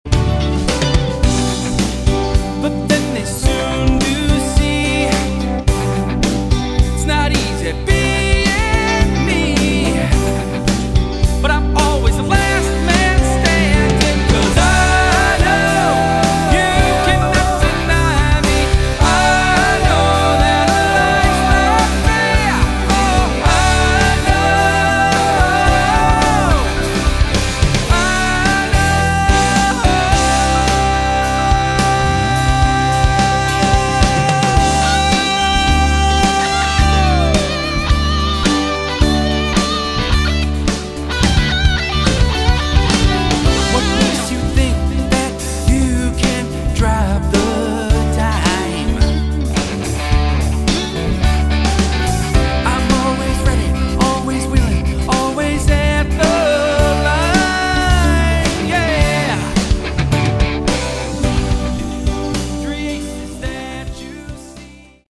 Category: AOR
lead vocals
guitars
bass guitar
drums & percussion
keyboards